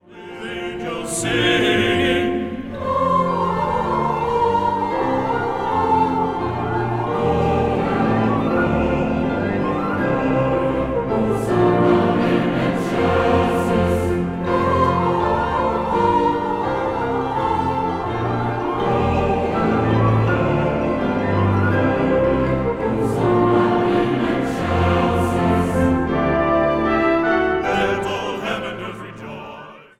Hoogtepunten uit het Doelenconcert 2017
fluit
hobo
trompet
hoorn
pauken
orgel
vleugel
Zang | Gemengd koor